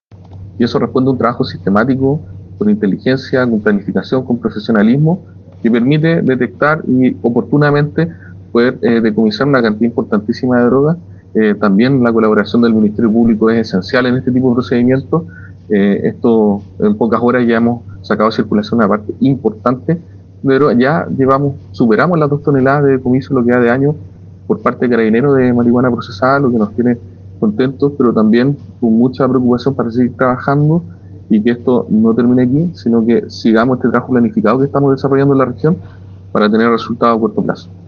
Escucha a Delegado Presidencial Regional, Galo Luna